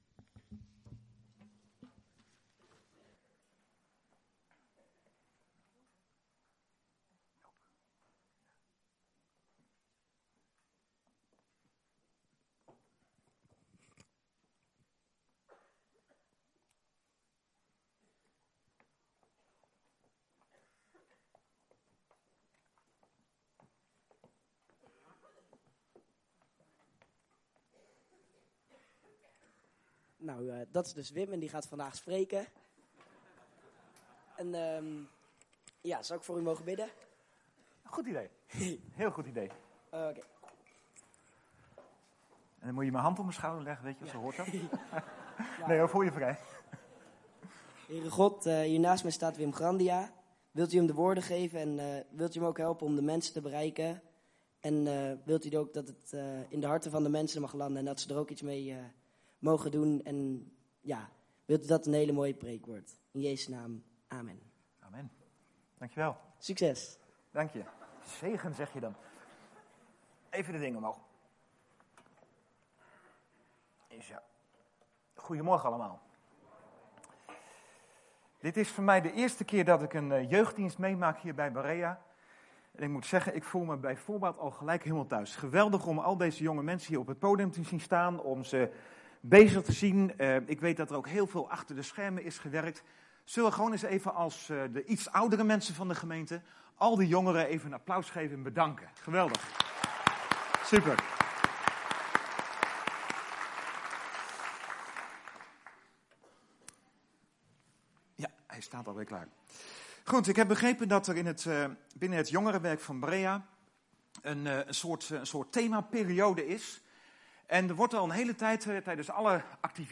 We komen elke zondagmorgen bij elkaar om God te aanbidden.